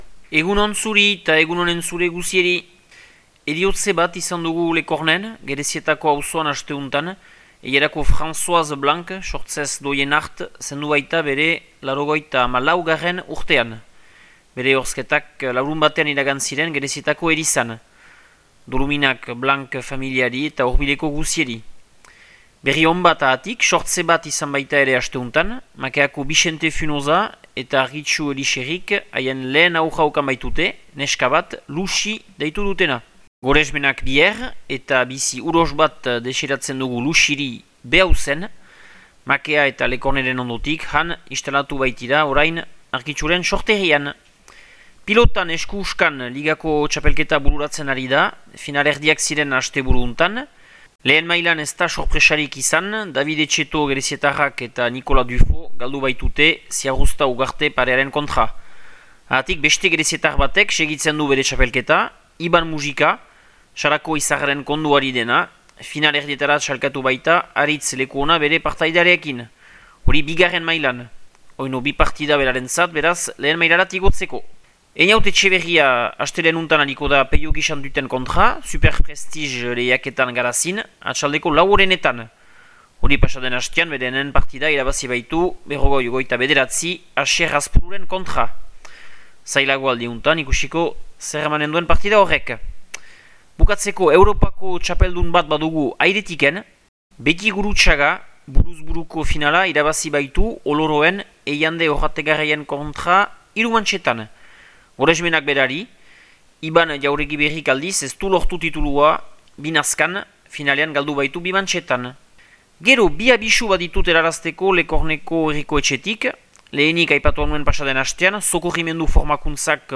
Abenduaren 1eko Makea eta Lekorneko berriak